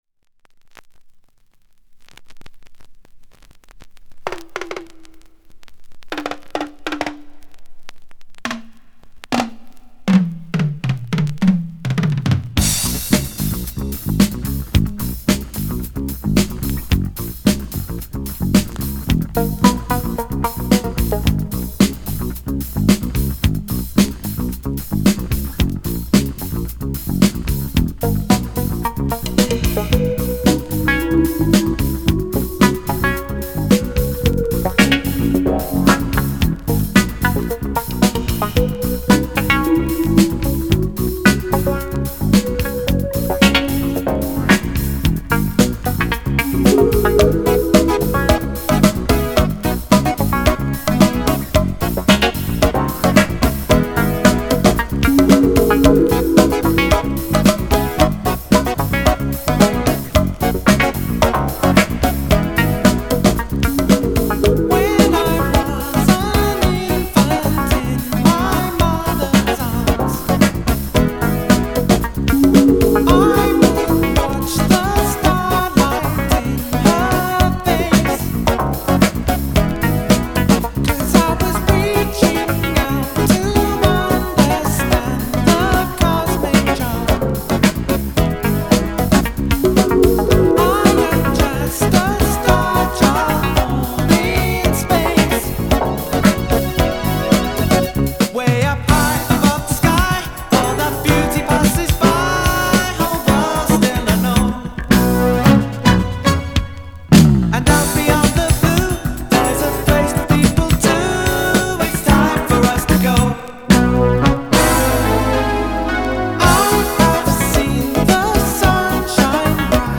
当時の空気感そのままDEEP HOUSE UNDERGROUND TRACK。